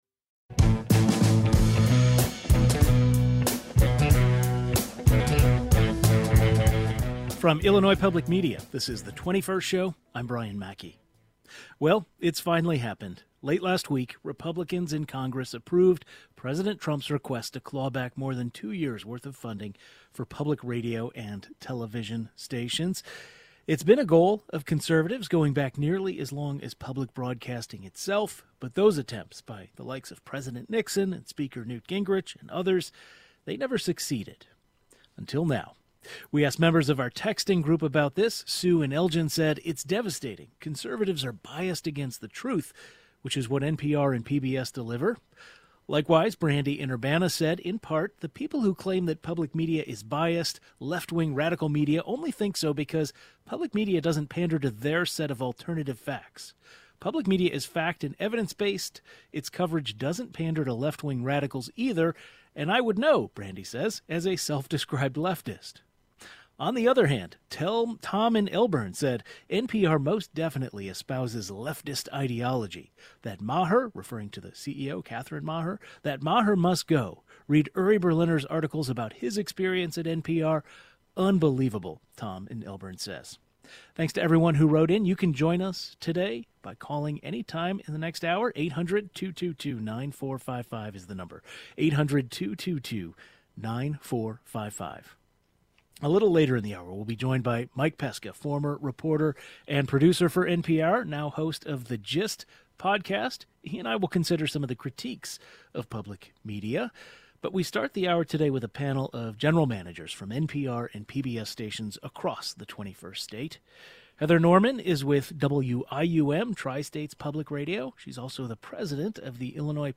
A panel of general managers from NPR and PBS stations across Illinois discuss how much their stations will lose after this move, where cuts may happen, and what are the options for alternative funding sources.